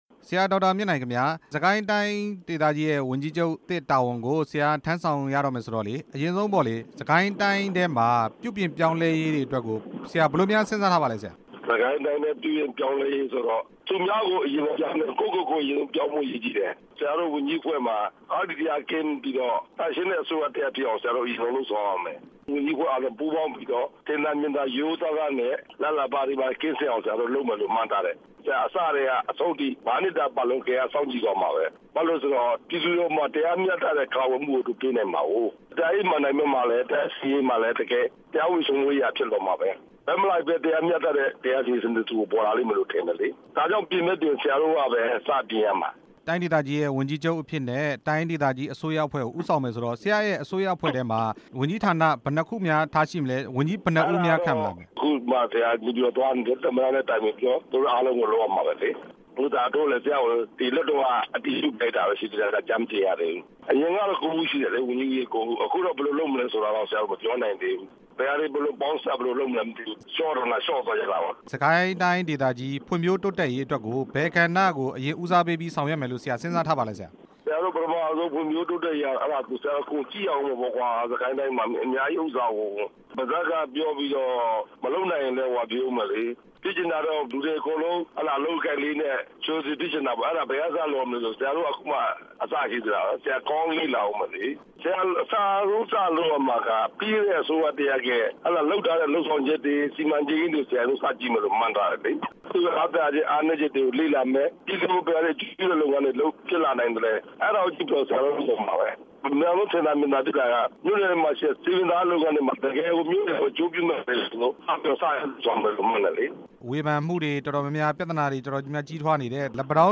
စစ်ကိုင်းတိုင်း ဝန်ကြီးချုပ် အသစ် ဒေါက်တာ မြင့်နိုင် နဲ့ မေးမြန်းချက်